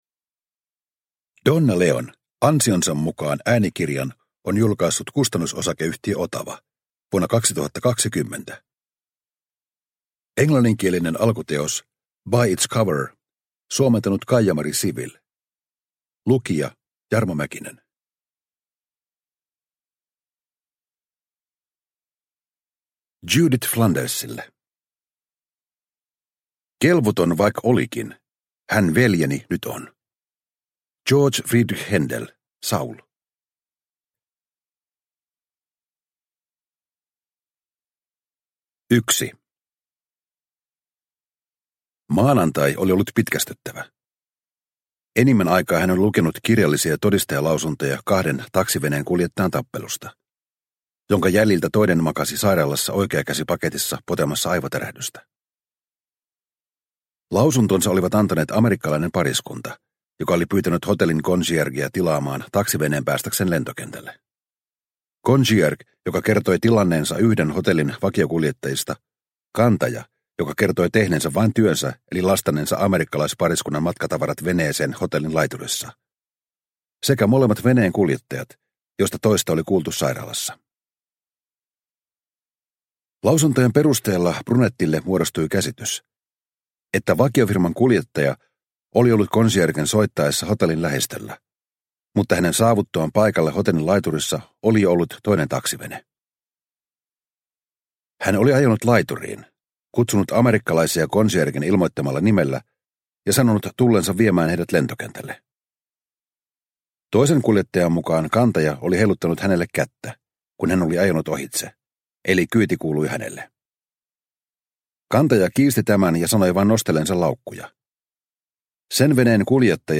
Ansionsa mukaan – Ljudbok – Laddas ner